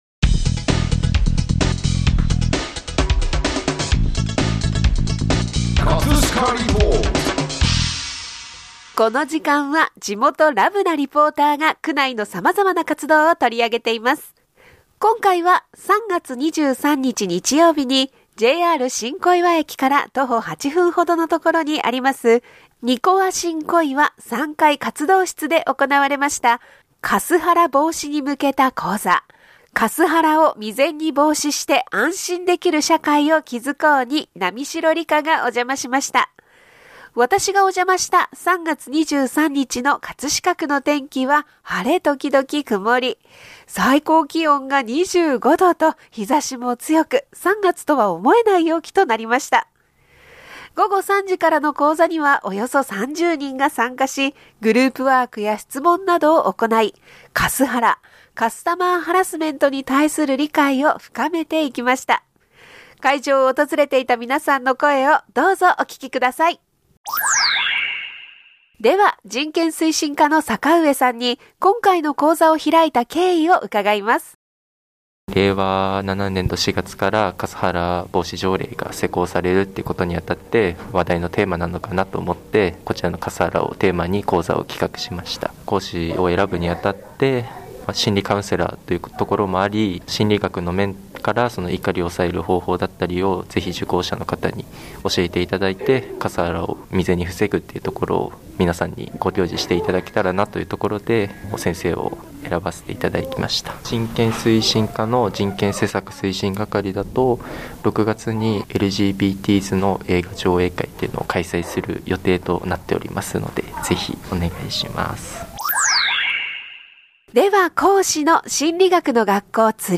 ▼リポート音声